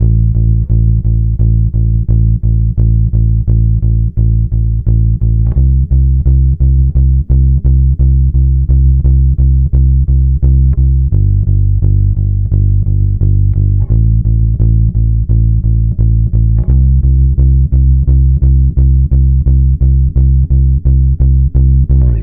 Track 02 - Bass 01.wav